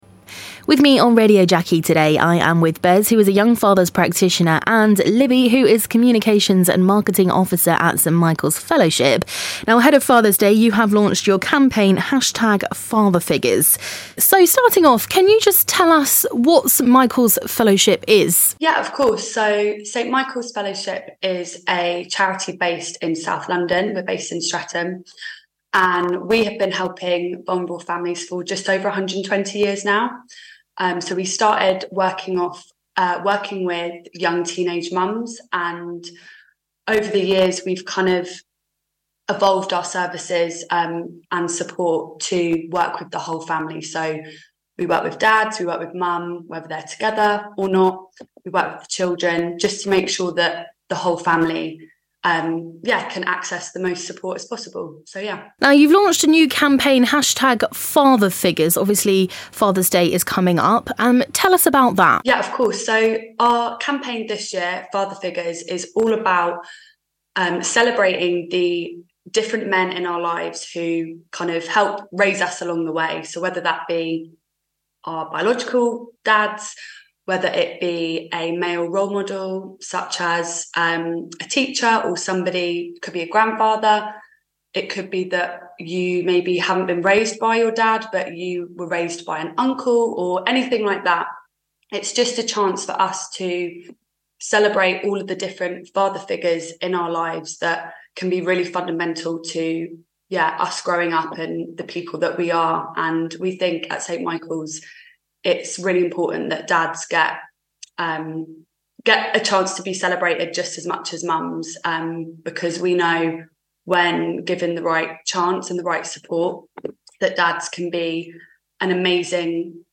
reports on the Father Figures campaign from St Michael's Fellowship in Streatham.